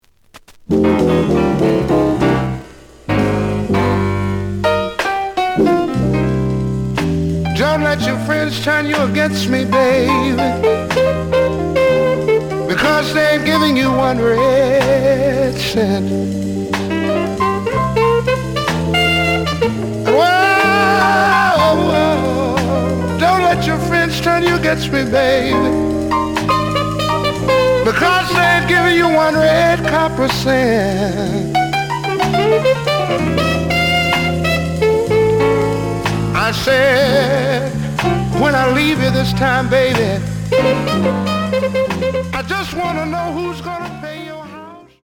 The audio sample is recorded from the actual item.
●Genre: Blues
Some noise on beginning of B side.)